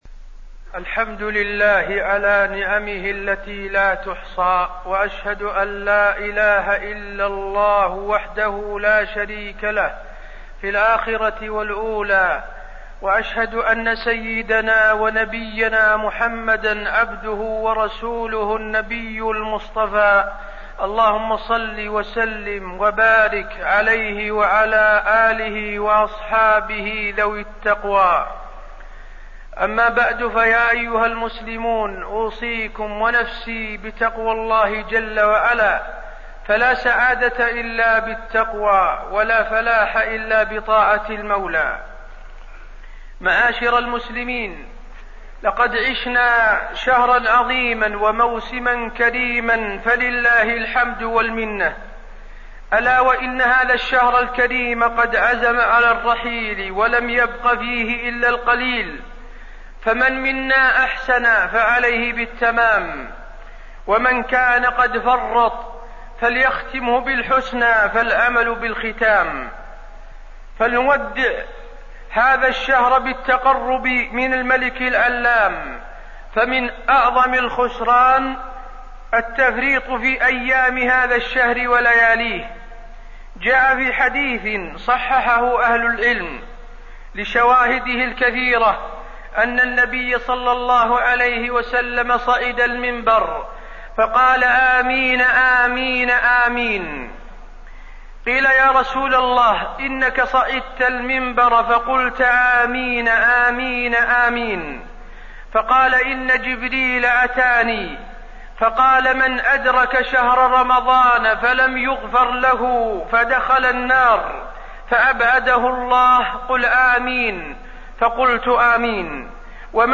تاريخ النشر ٢٤ رمضان ١٤٣١ هـ المكان: المسجد النبوي الشيخ: فضيلة الشيخ د. حسين بن عبدالعزيز آل الشيخ فضيلة الشيخ د. حسين بن عبدالعزيز آل الشيخ رحيل رمضان